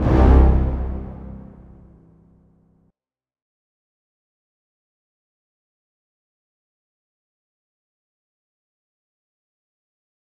Hit (3).wav